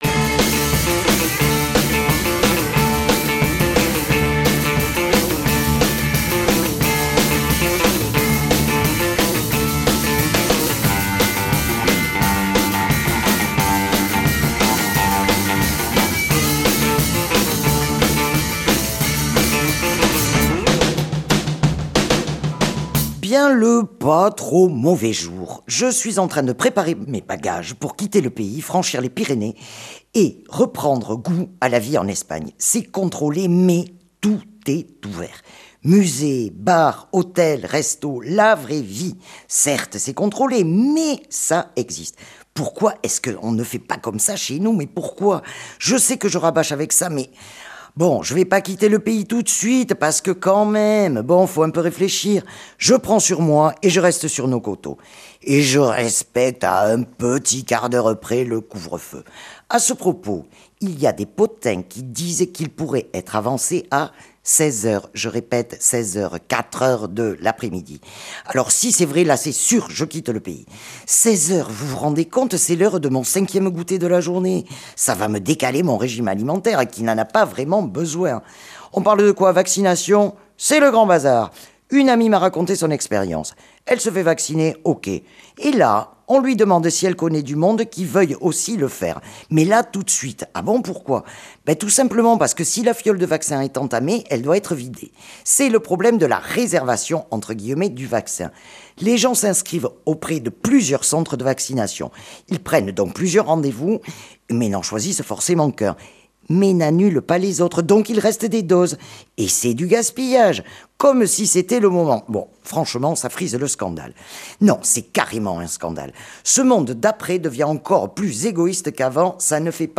Au programme interviews, musiques et l'oreille du motard.